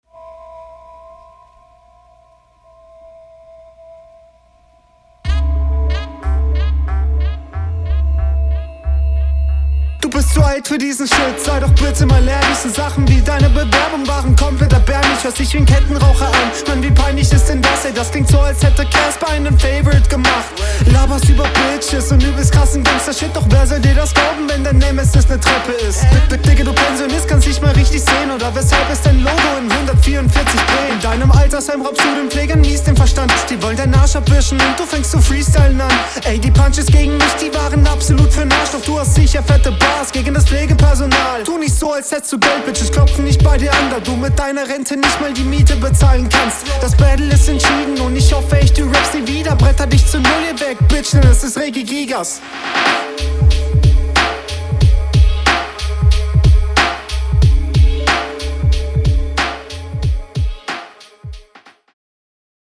Man versteht dich schon mal deutlich besser als deinen Gegner.